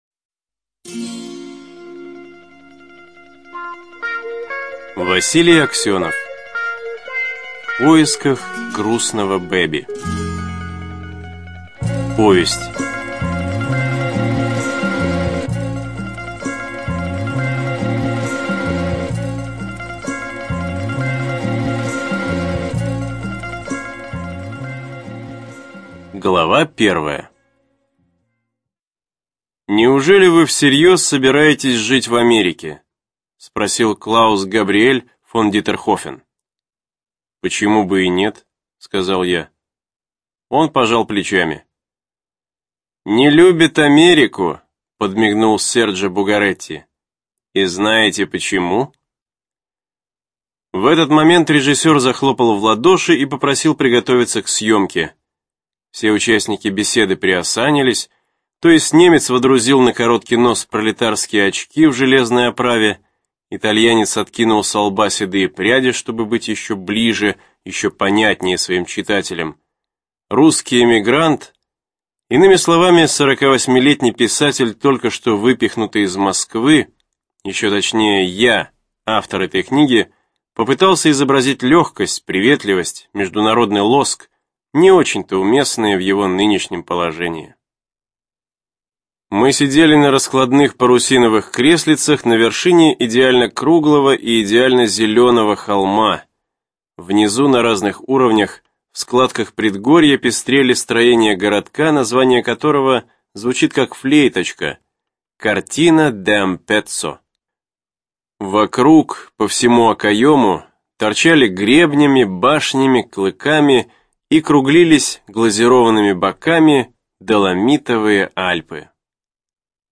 Студия звукозаписиРавновесие